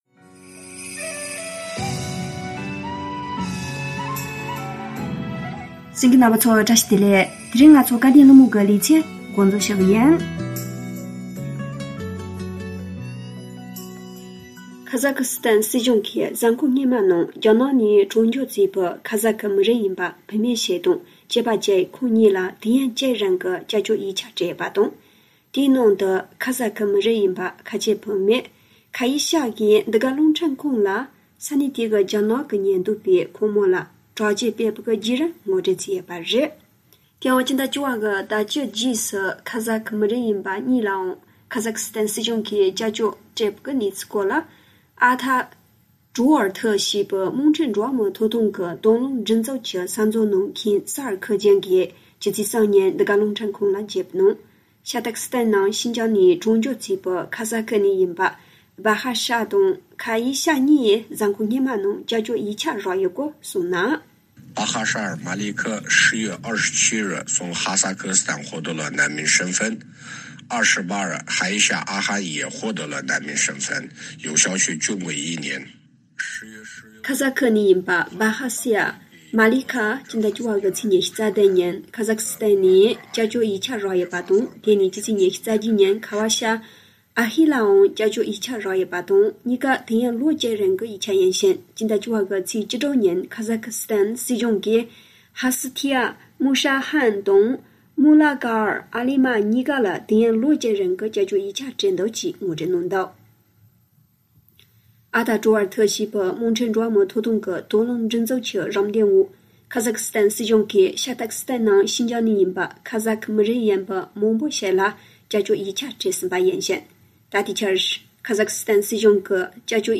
༄༅།།ཐེངས་འདིའི་དམིགས་བསལ་གསར་འགྱུར་ནང་།